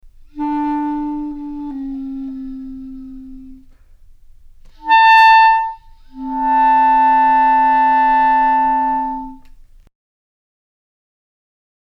When a multiple sonority is produced, it appears that there are actually two tube lengths at work simultaneously (Example #1).
01 145-multiphonic explanation.mp3